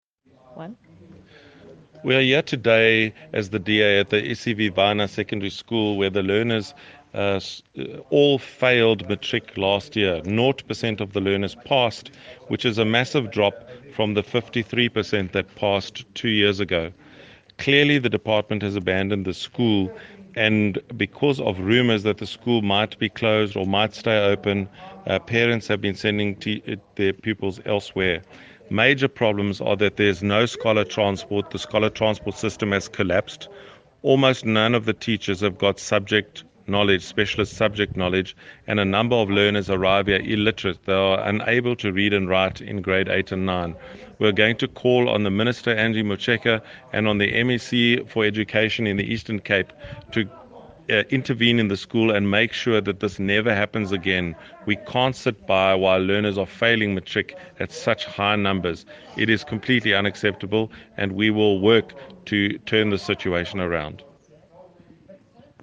Issued by Ian Ollis MP – DA Shadow Minister of Basic Education
Please find attached a soundbite in